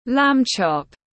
Sườn cừu non tiếng anh gọi là lamb chop, phiên âm tiếng anh đọc là /læm ʧɒp/
Lamb chop /læm ʧɒp/